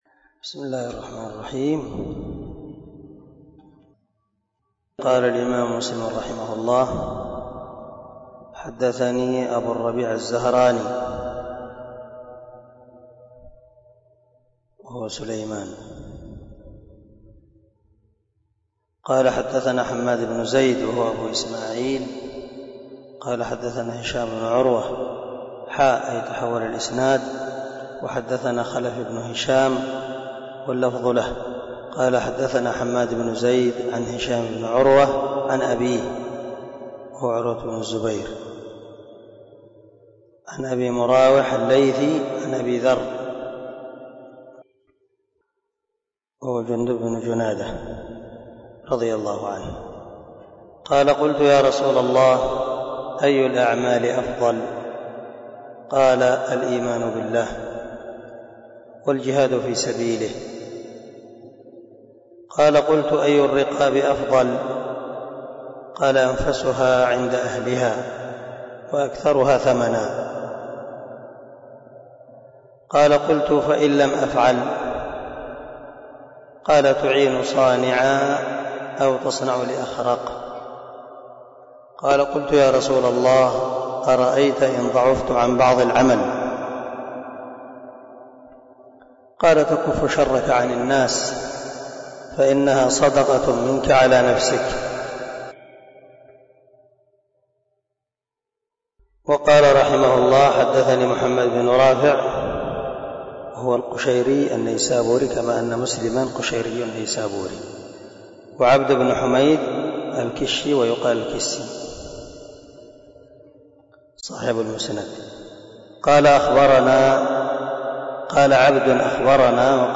057الدرس 56 من شرح كتاب الإيمان حديث رقم ( 84 - 85 ) من صحيح مسلم